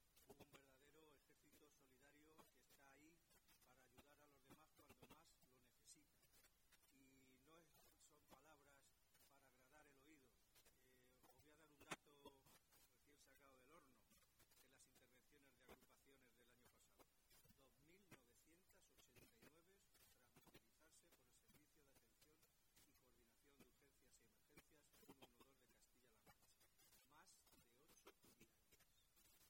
El director general de Protección Ciudadana, Emilio Puig, ha remarcado el dato de intervenciones realizadas por Protección Civil durante 2023, en el acto de entrega de los lotes de uniformidad a las agrupaciones de Albacete.